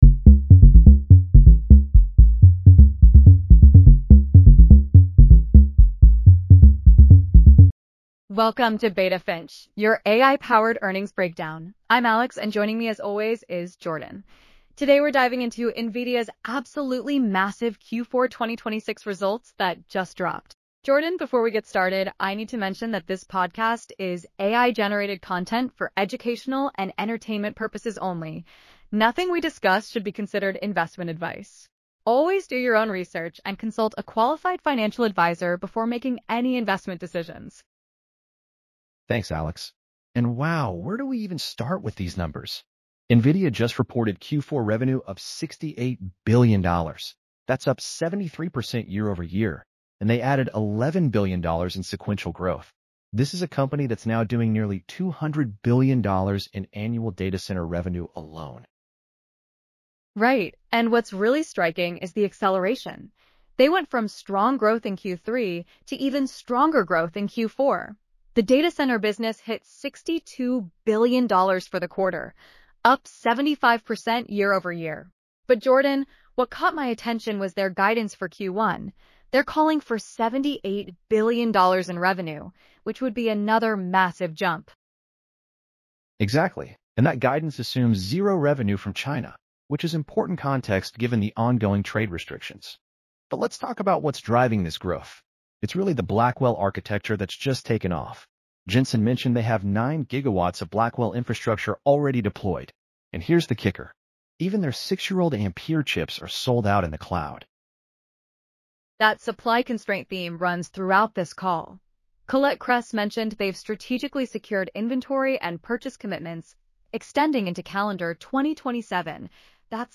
Beta Finch Podcast Script: NVIDIA Q4 2026 Earnings